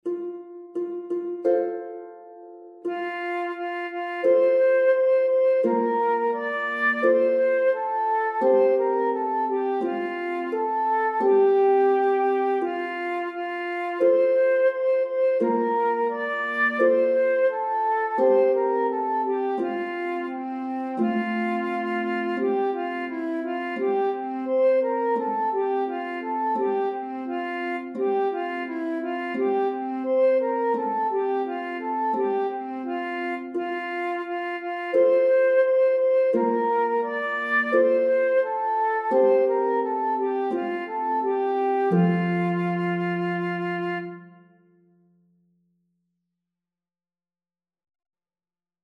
Speelman zingt '